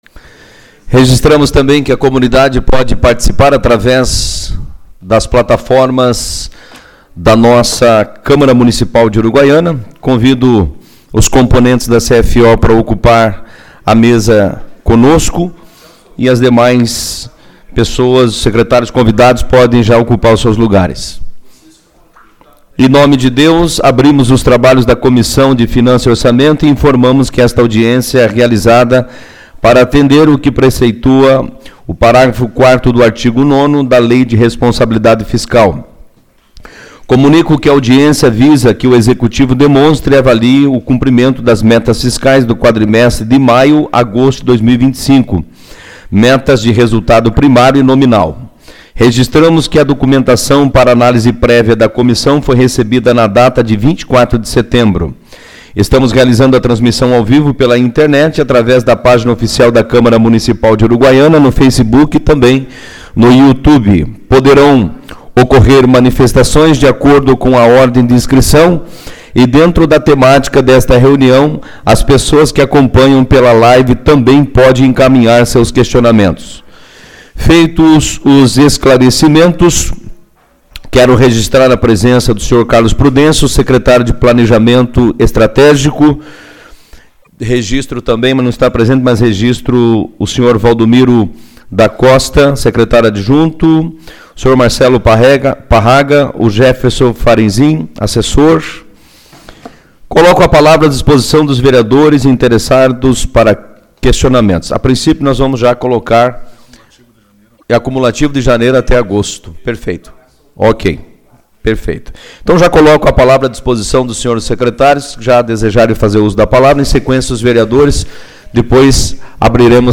26/09 - Audiência Pública-Metas Fiscais 2º Quadrimestre